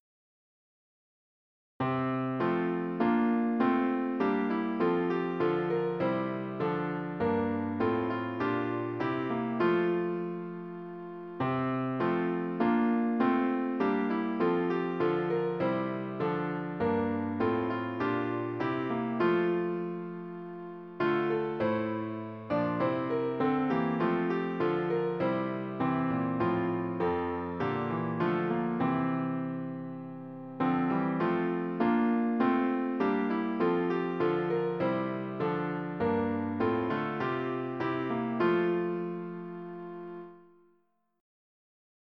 MIDI Music File